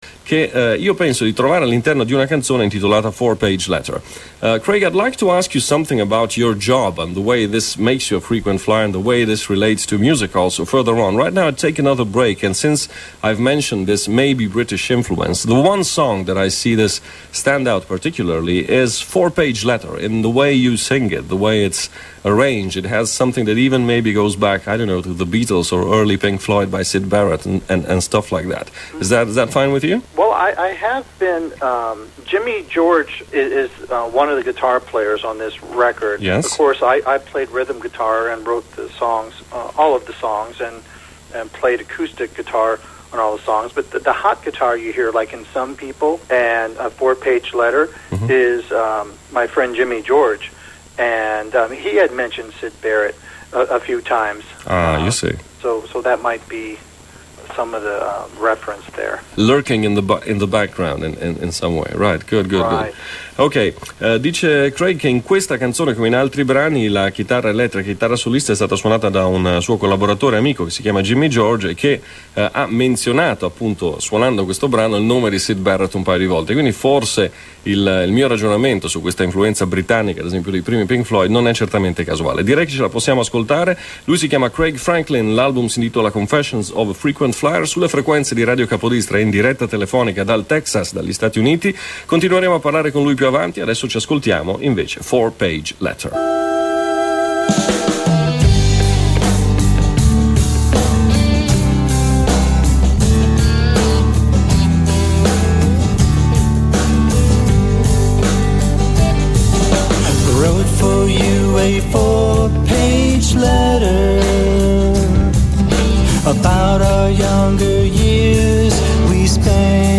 Four Page Letter - Radio Capodistria Interview